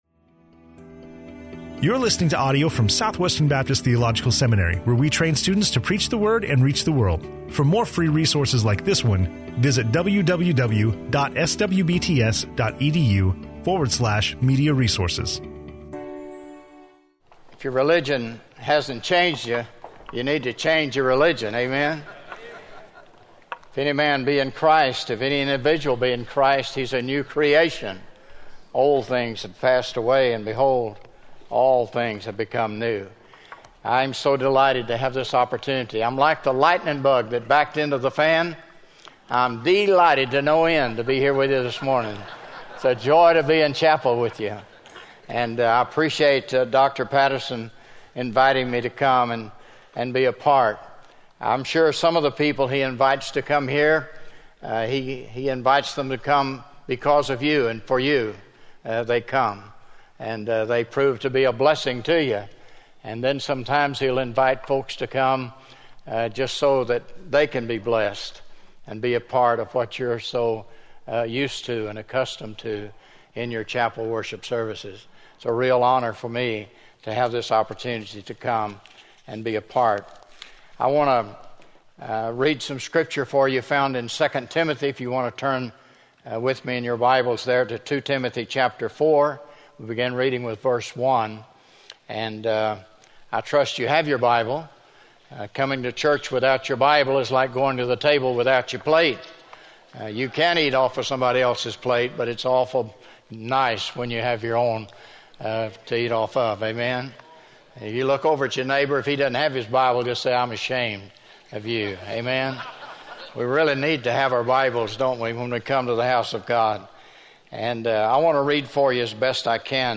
SWBTS Chapel Sermons